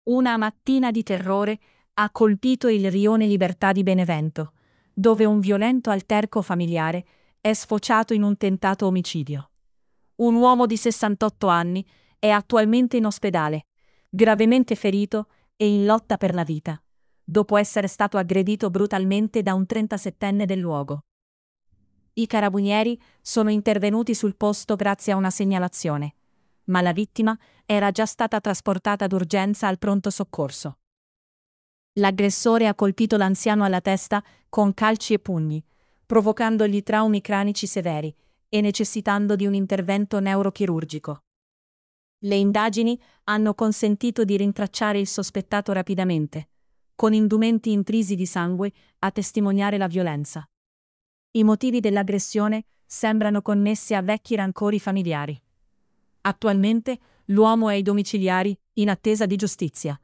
benevento-sangue-nel-rione-liberta-68enne-in-fin-di-vita-arrestato-un-trentasettenne-tts-1.mp3